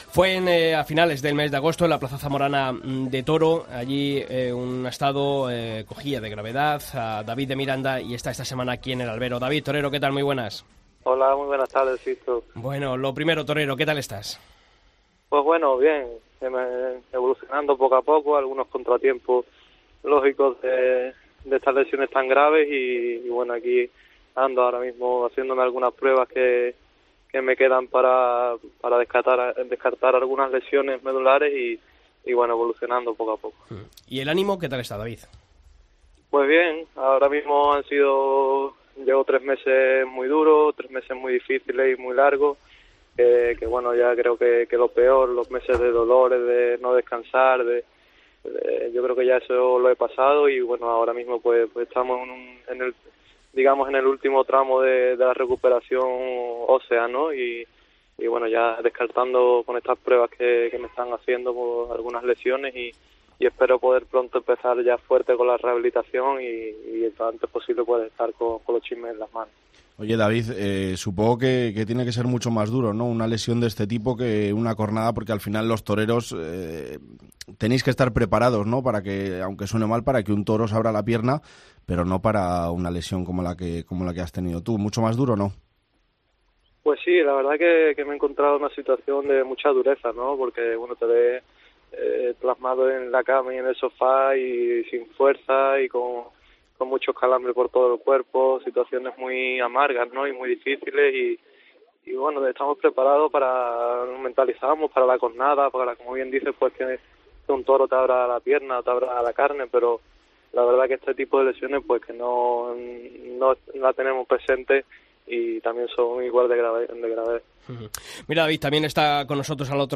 David de Miranda atendía esta semana la llamada de El Albero desde el hospital de Sevilla, donde iba a ser sometido a nuevas pruebas médicas.